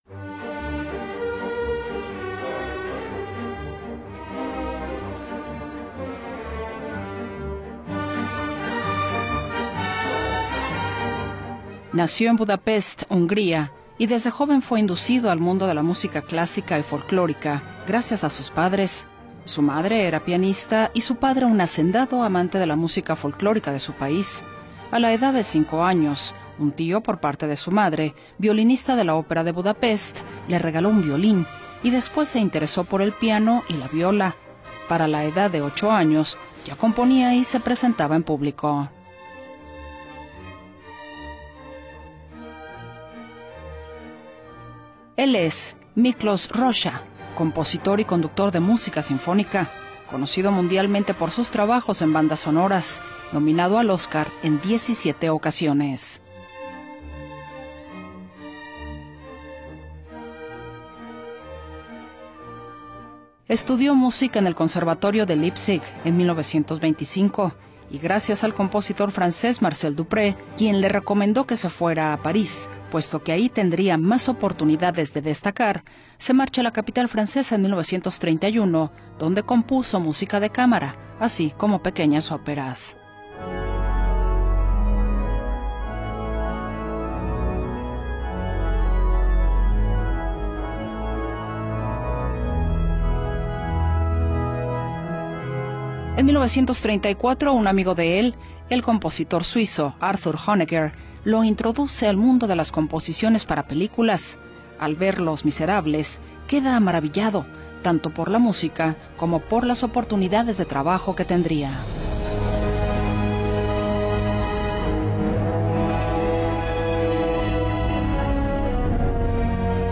Su estilo se caracteriza por melodías dramáticas, orquestaciones poderosas y un fuerte uso de motivos folclóricos húngaros.